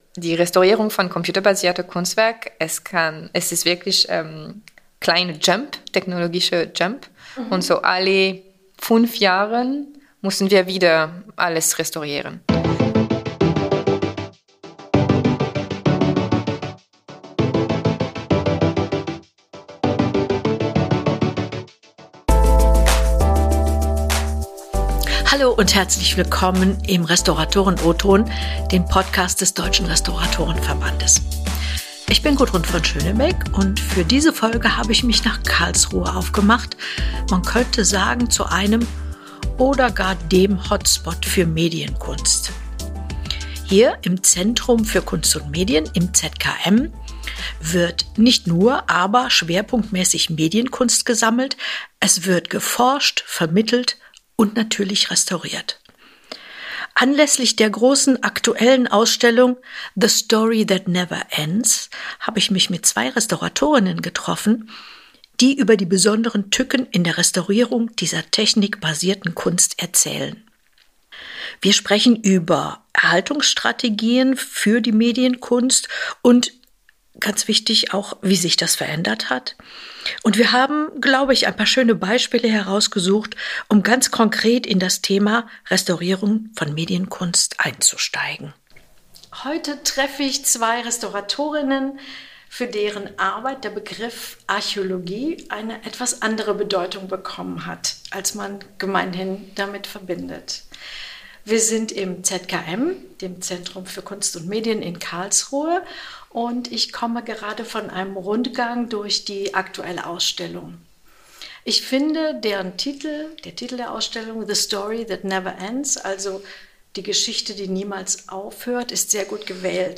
Beschreibung vor 4 Monaten Für diese Podcast-Folge haben wir das Zentrum für Kunst und Medien in Karlsruhe besucht.